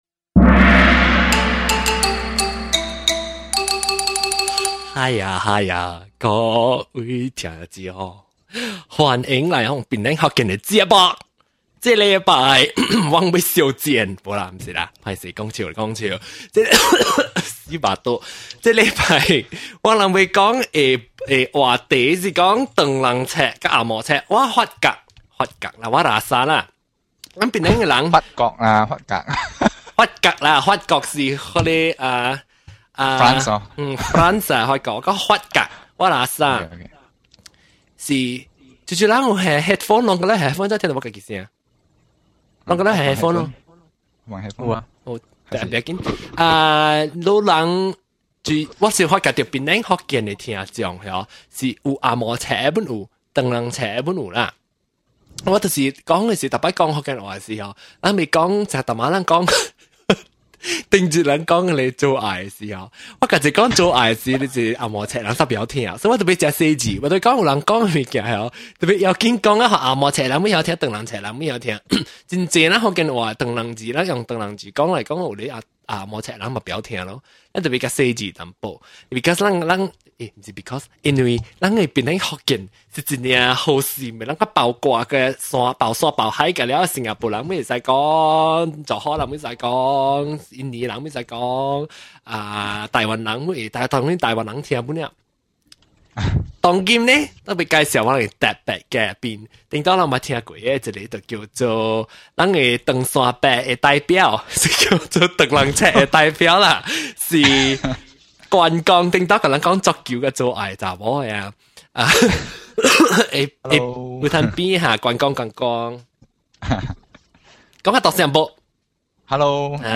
An open discussion about the English educated and Chinese educated people.